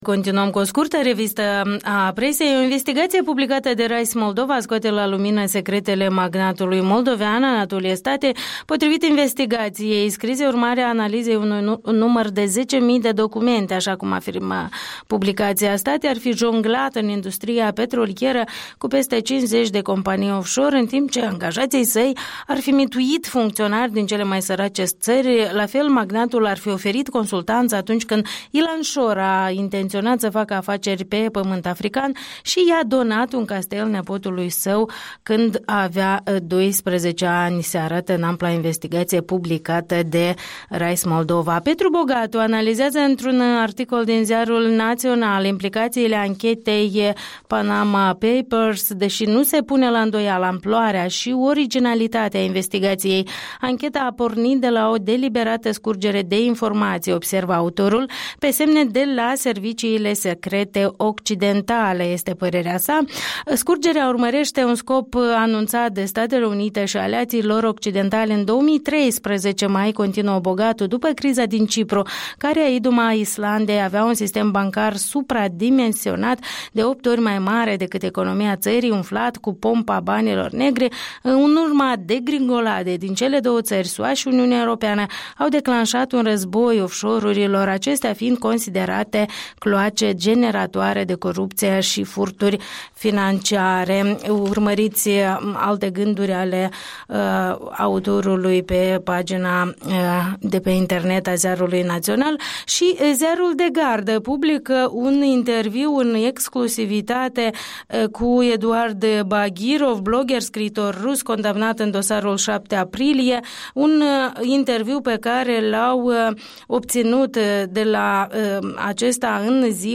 Revista presei